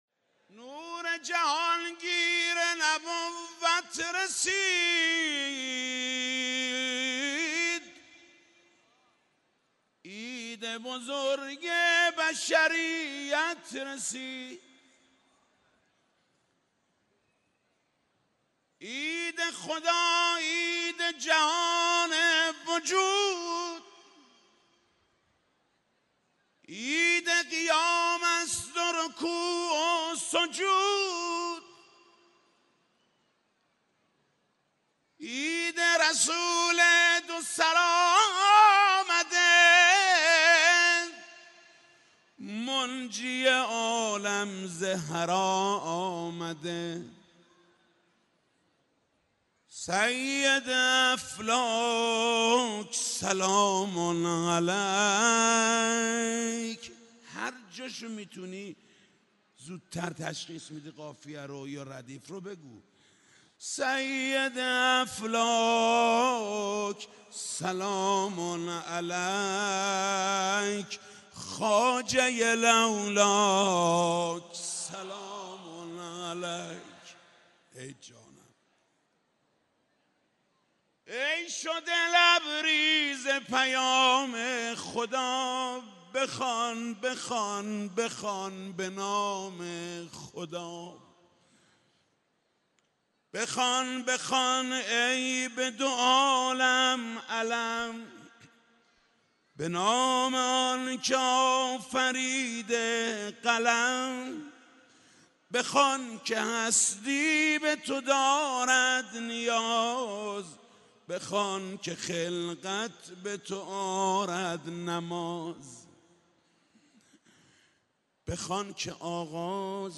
دانلود مبعث پیامبر سال 99 دانلود مولودی بعثت رسول اکرم